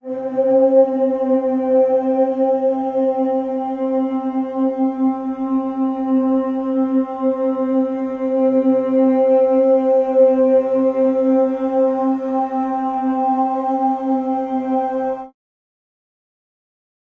描述：使用颗粒合成（我自己的实现）对尺八样本进行了大量处理
Tag: 粒状 尺八 合成